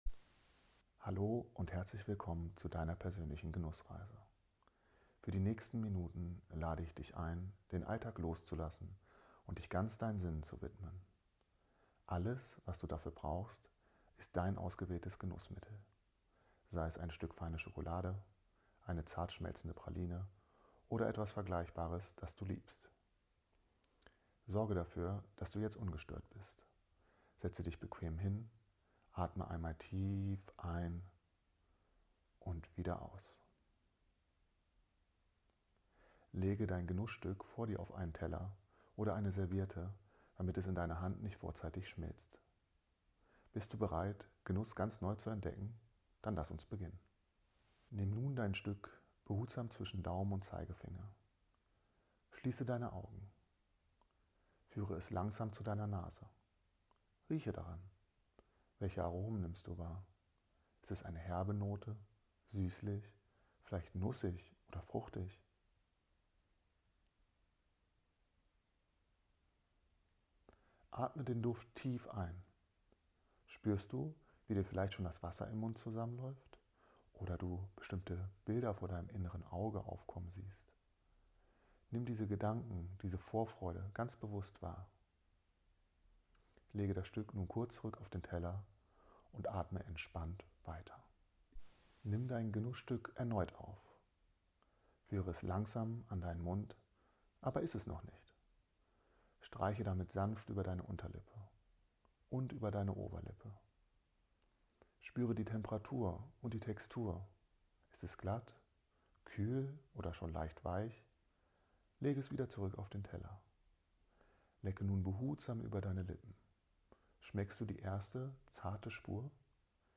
SchokoladentraumreiseGeführte Genussübung